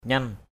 /ɲʌn/ 1.
nyan.mp3